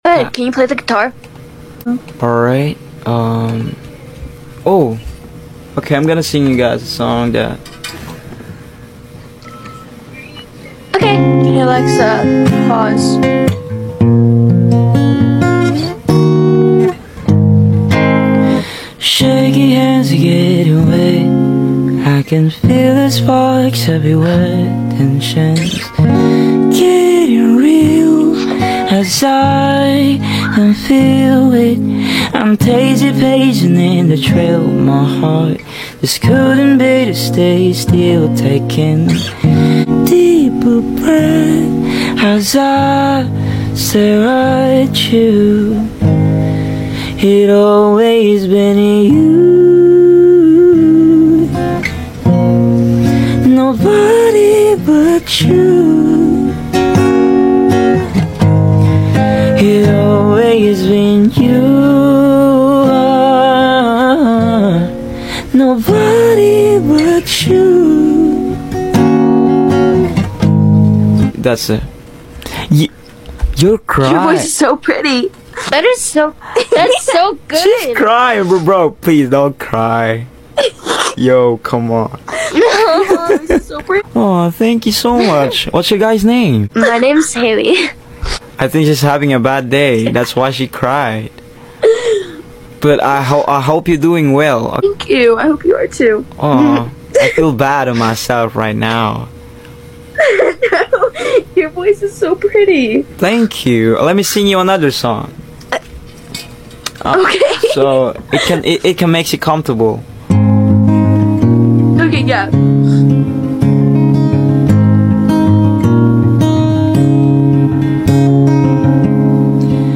Singing to strangers...